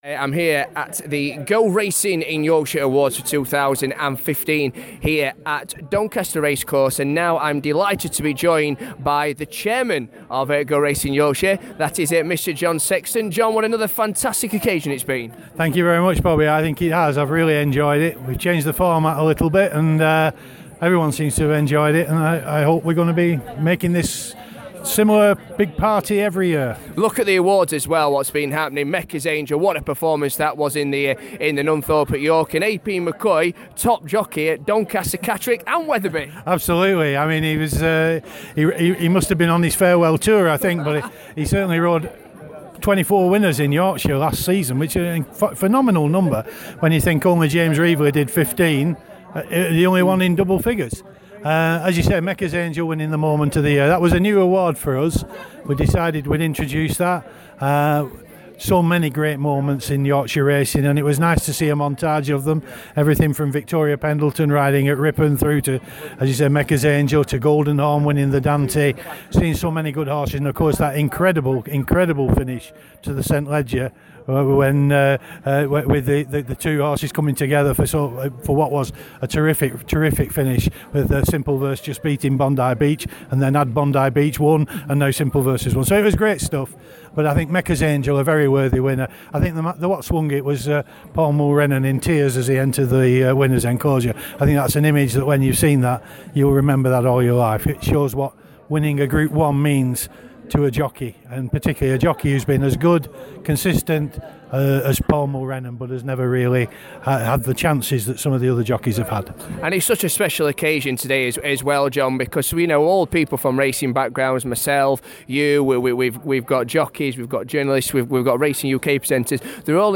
At the Go Racing in Yorkshire awards